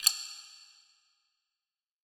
BWB WAVE 3 PERC (36).wav